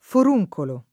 vai all'elenco alfabetico delle voci ingrandisci il carattere 100% rimpicciolisci il carattere stampa invia tramite posta elettronica codividi su Facebook foruncolo [ f or 2j kolo ] (antiq. furuncolo e furoncolo ) s. m.